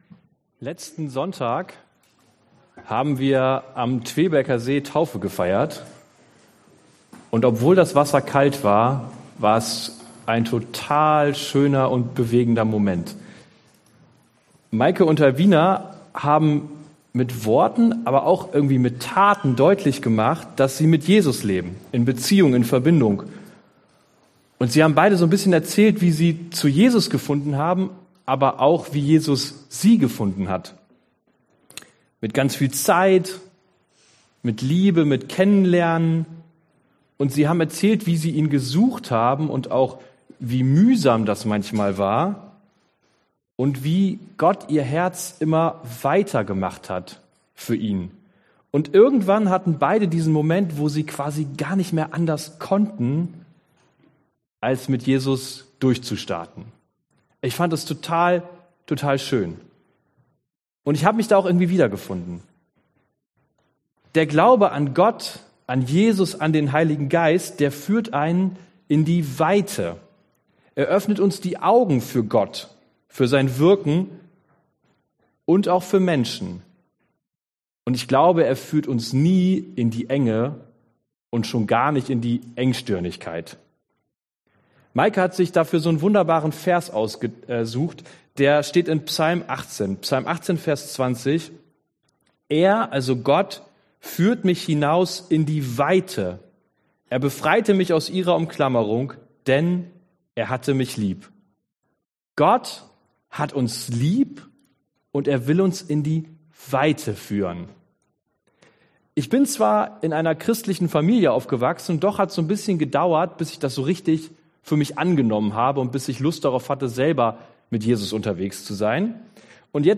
Passage: Lukas 5,12-16 Dienstart: Predigt